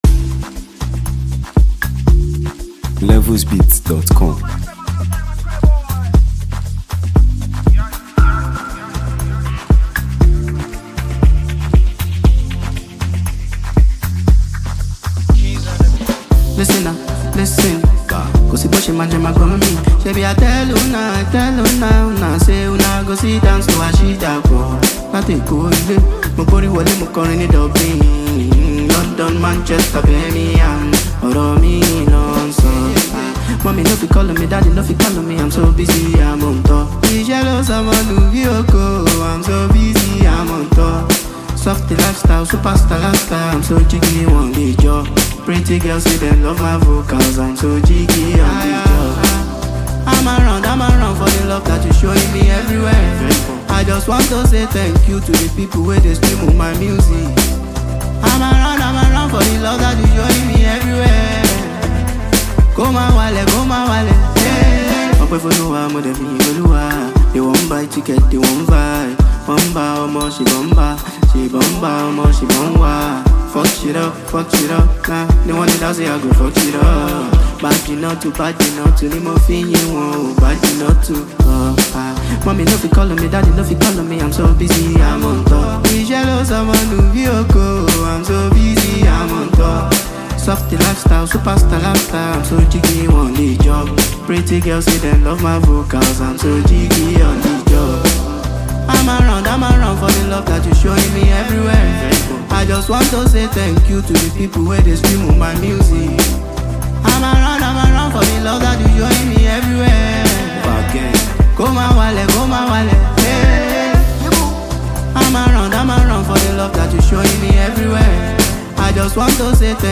Nigeria Music